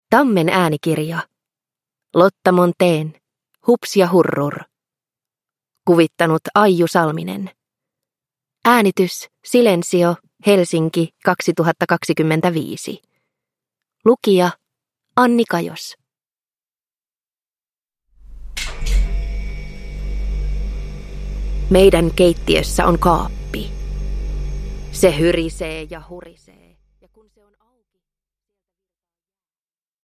Hups ja Hurrur – Ljudbok